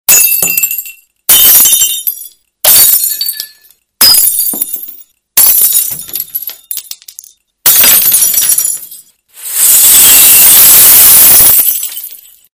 glass_break.mp3